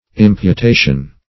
Imputation \Im`pu*ta"tion\, [L. imputatio an account, a charge: